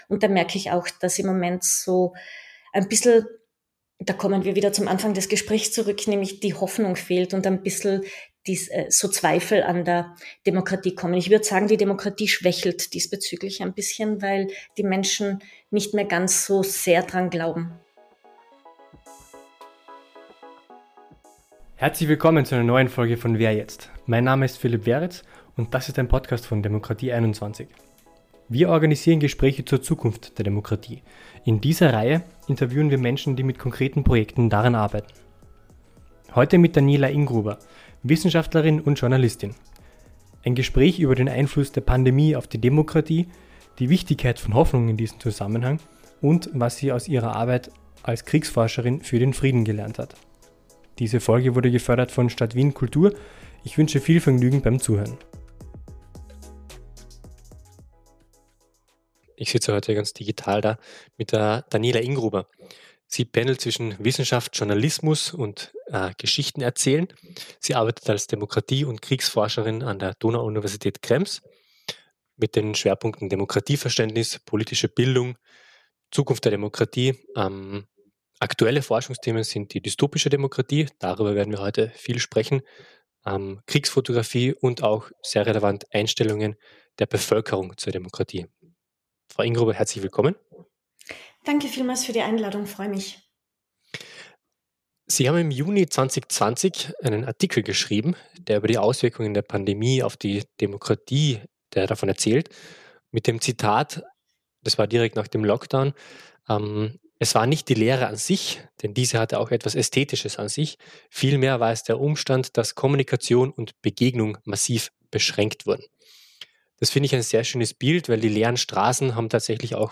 Lesen Sie hier zwei Stichpunkte aus dem Gespräch.